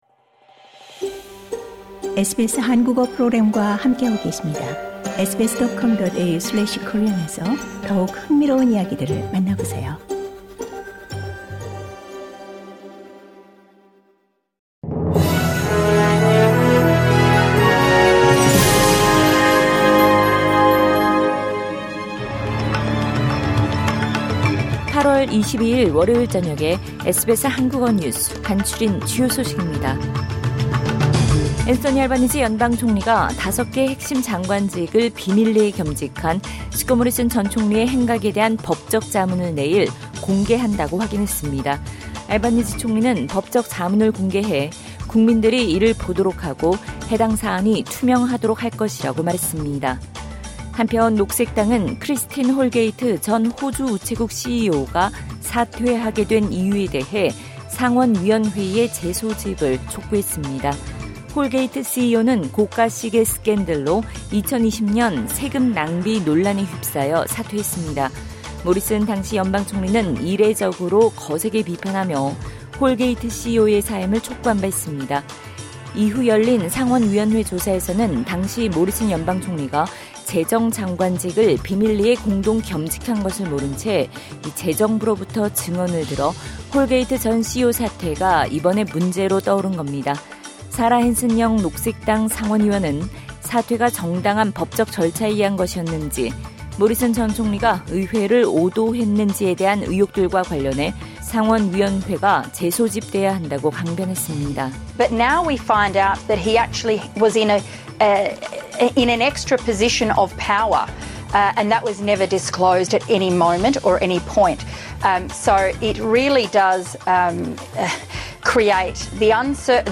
SBS 한국어 저녁 뉴스: 2022년 8월 22일 월요일